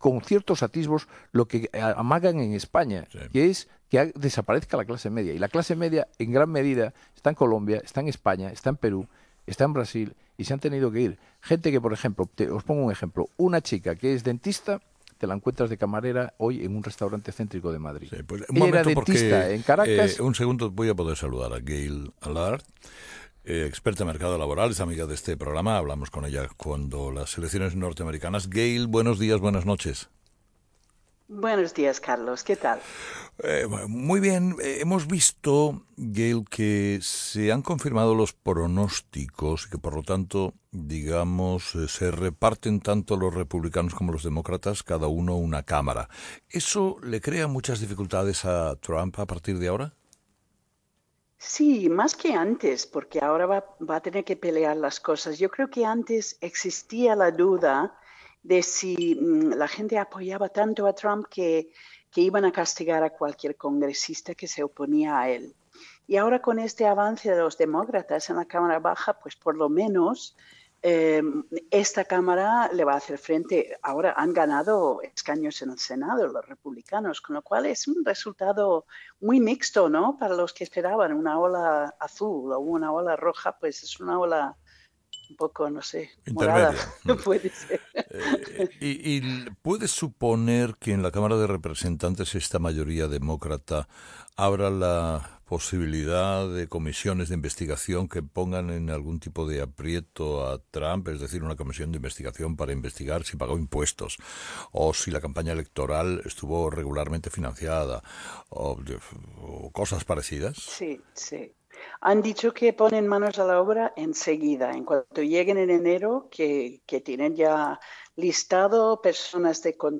Comments on Spanish radio about US midterm elections
Talking to Carlos Herrera on Spain’s top morning news show.